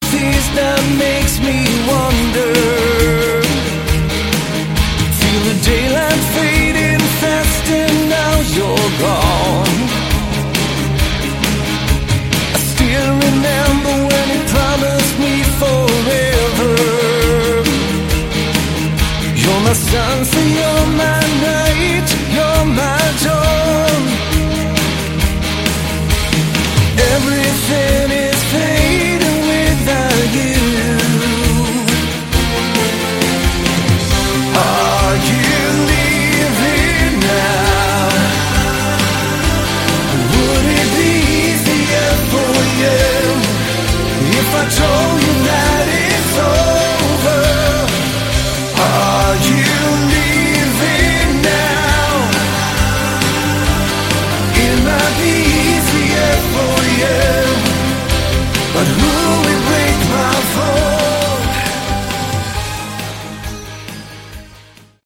Category: Melodic Rock
lead and backing vocals, acoustic guitars
electric guitars
lead guitars
bass and backing vocals
keyboards
drums